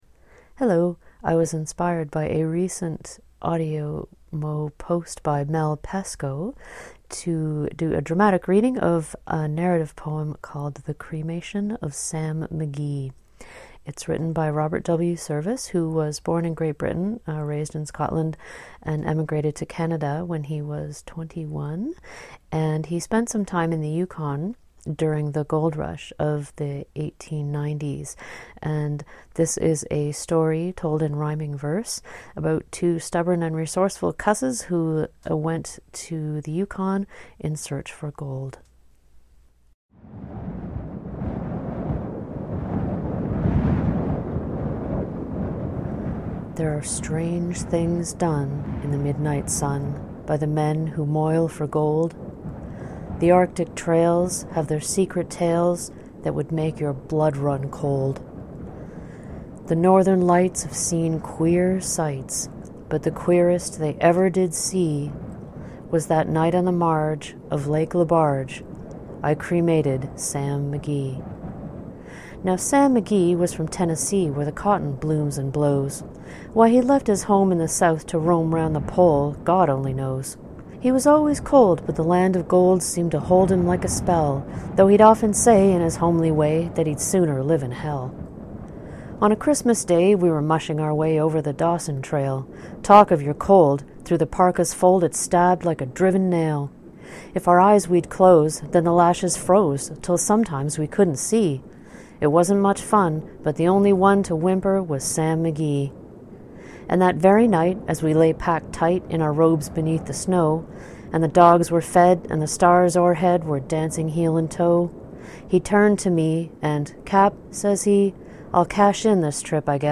Day10, A Dramatic Reading of The Cremation of Sam McGee, it'll make you shiver!